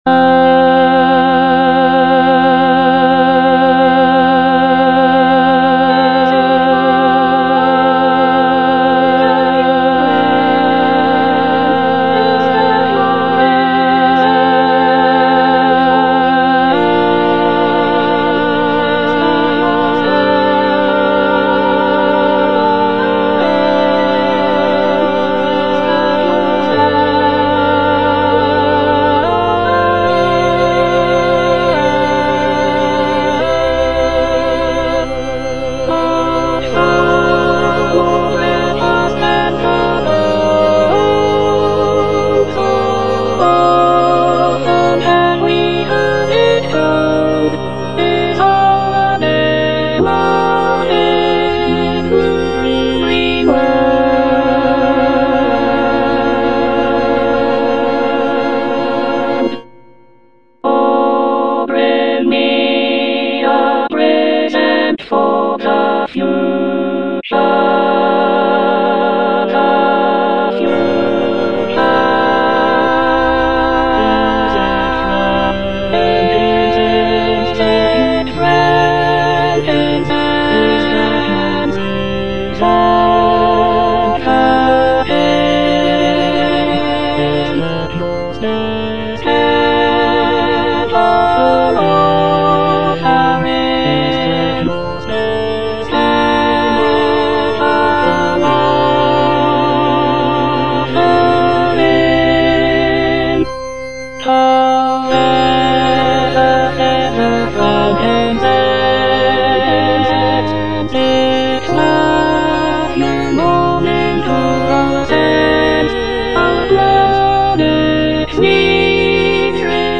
Alto I (Emphasised voice and other voices)
is a choral work